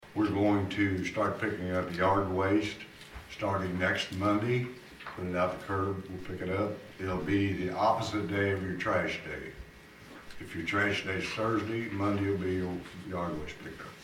Ward 4 Councilman Tom Hagedorn announced the start date for citywide yard waste pick up.